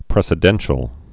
(prĕsĭ-dĕnshəl)